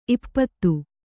♪ ippattu